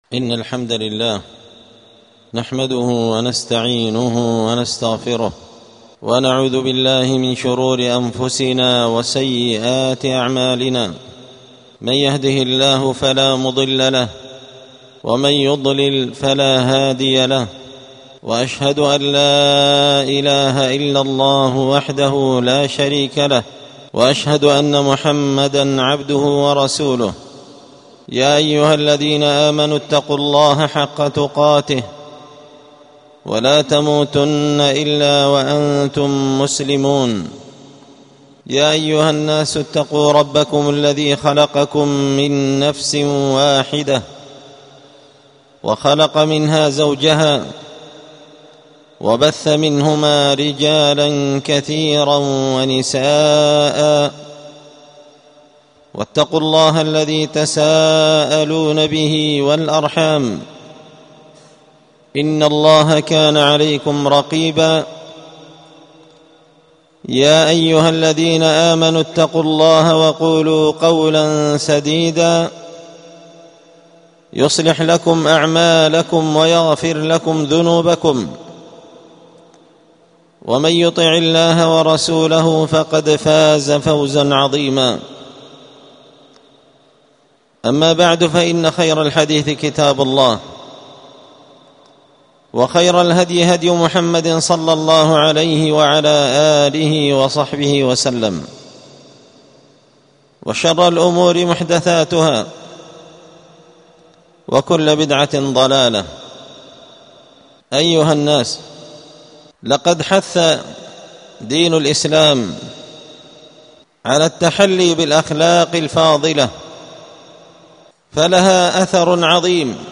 ألقيت هذه الخطبة بدار الحديث السلفية بمسجد الفرقان
الجمعة 23 جمادى الأولى 1447 هــــ | الخطب والمحاضرات والكلمات | شارك بتعليقك | 83 المشاهدات